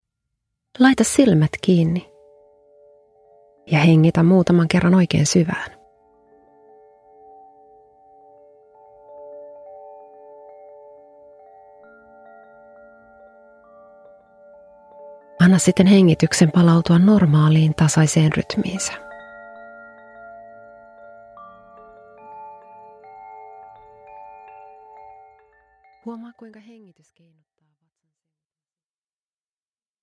Stressinhallintameditaatio 5 min – Ljudbok – Laddas ner